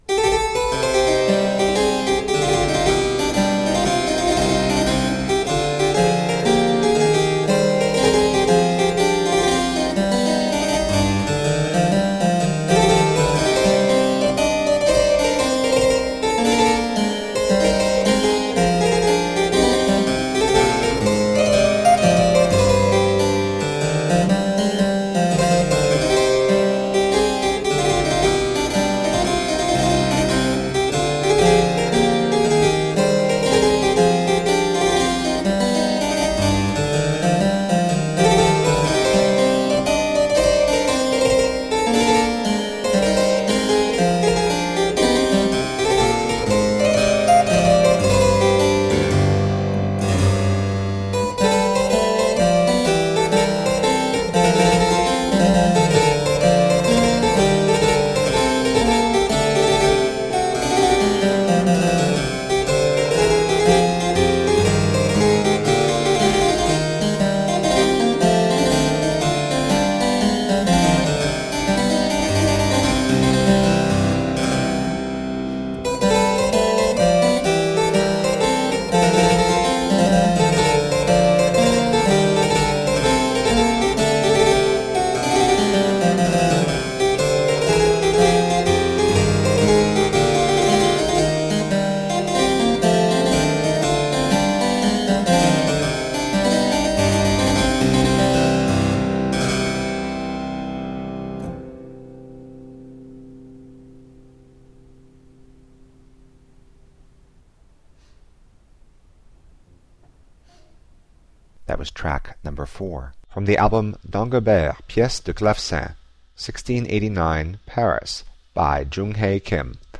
Deeply elegant harpsichord.